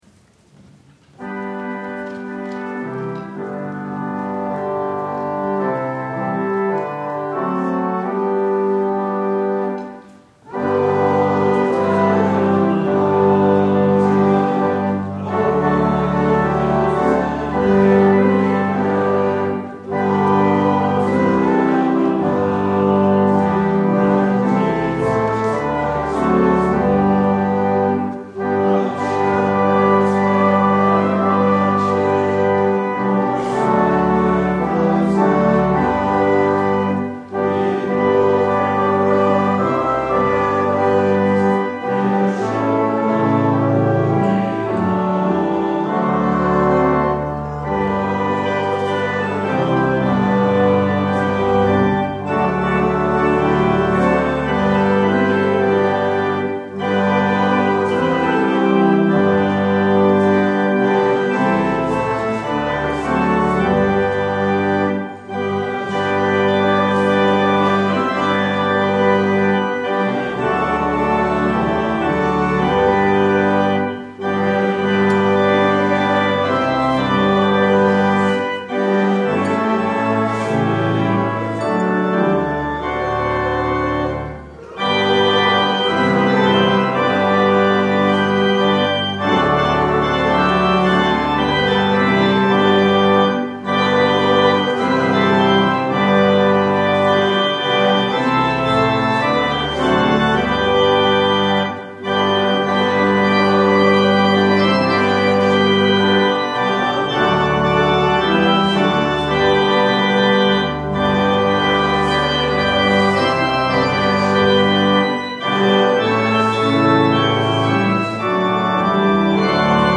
25 Closing Hymn.mp3